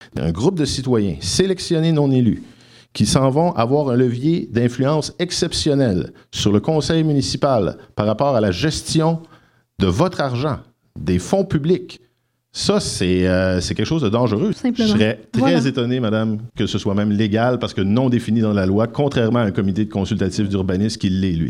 Une trentaine de personnes étaient sur place pour assister à cet échange vif mais courtois, mercredi soir, qui portait notamment sur les thèmes de l’économie, de l’environnement et de la gestion des terres publiques.
Le maire sortant a vivement critiqué la formule de « comité d’assainissement des finances publiques » proposée par son adversaire qui, selon lui, représenterait un danger pour la démocratie: